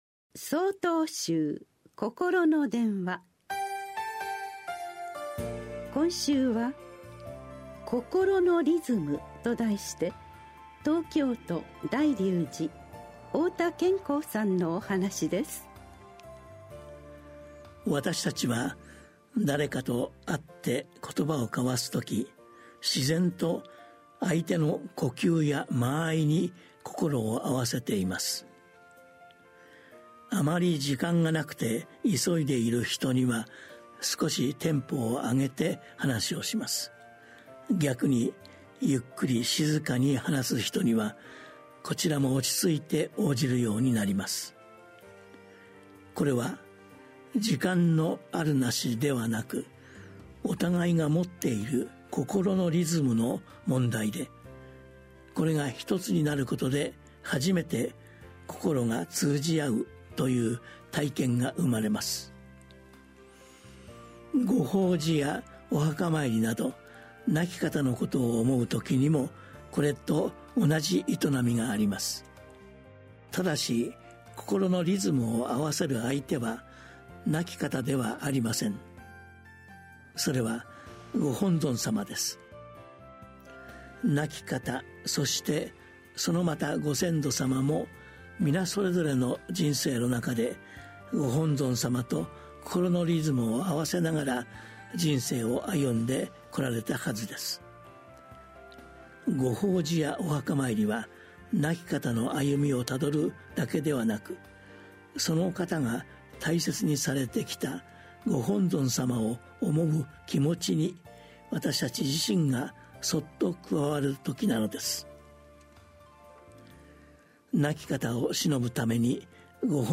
心の電話（テレホン法話）４/21 公開『心のリズム』 | 曹洞宗 曹洞禅ネット SOTOZEN-NET 公式ページ